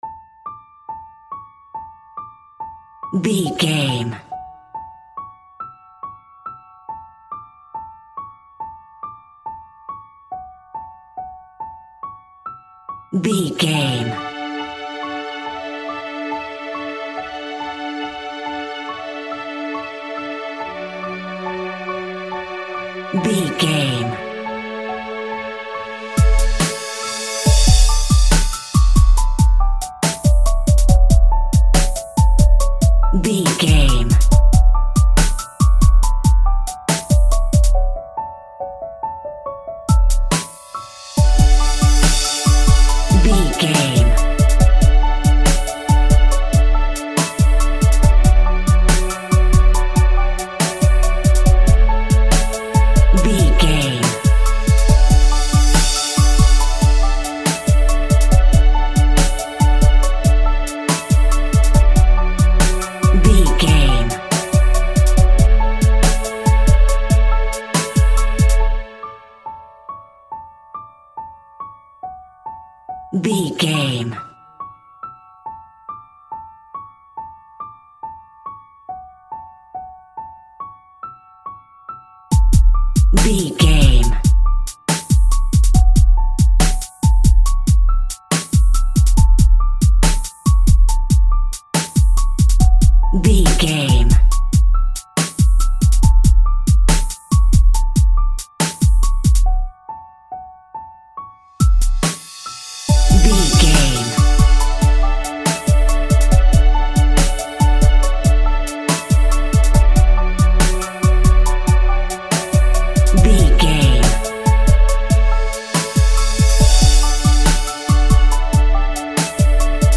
Classical Hip Hop with Piano Fusion.
Ionian/Major
D
chilled
laid back
groove
hip hop drums
hip hop synths
hip hop pads